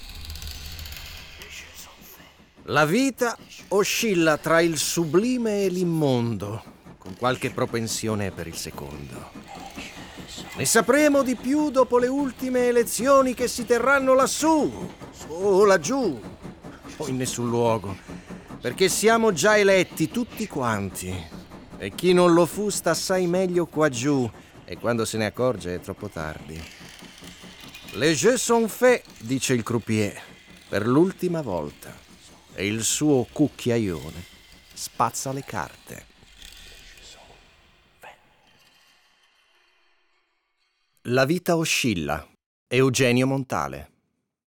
Abbiamo immaginato un contenitore dove si possano ascoltare delle prime letture poetiche.